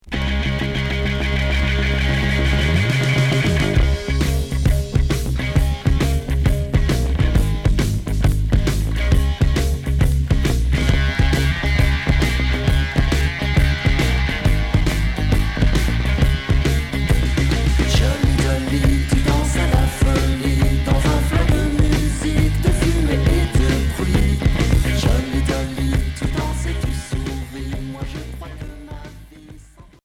Glam Rock Unique 45t retour à l'accueil